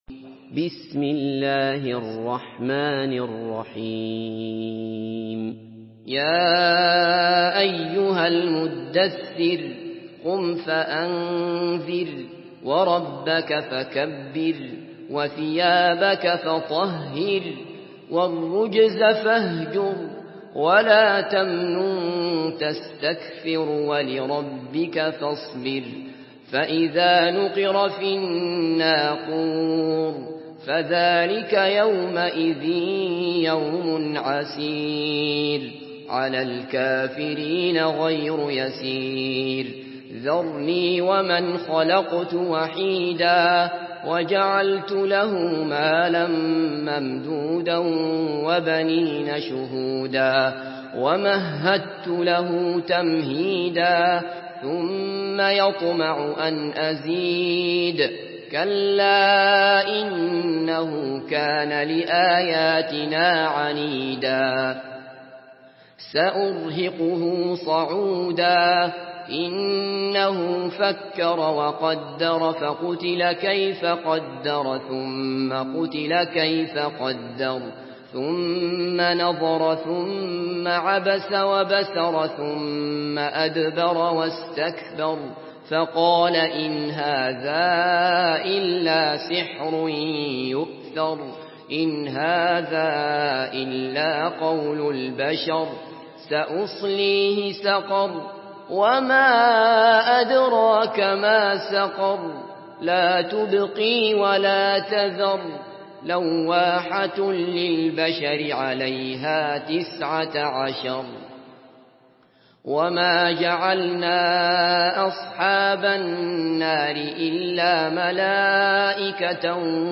Surah المدثر MP3 by عبد الله بصفر in حفص عن عاصم narration.